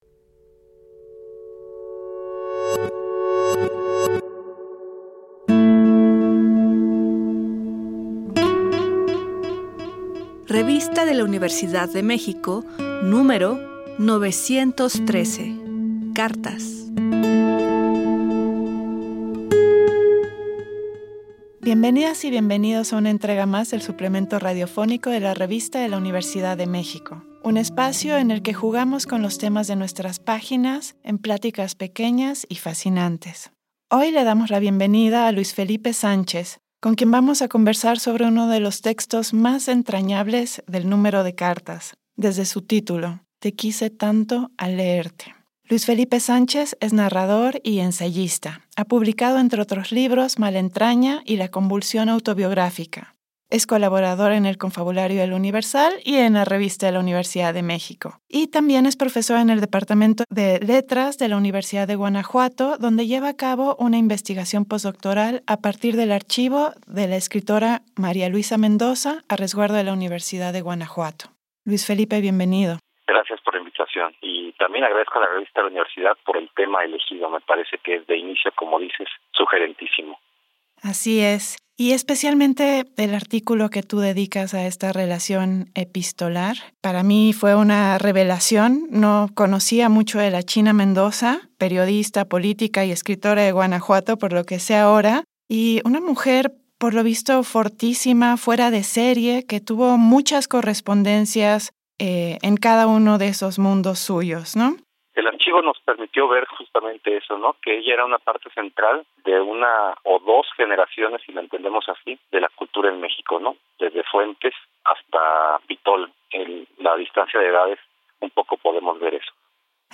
Fue transmitido el jueves 24 de octubre de 2024 por el 96.1 FM.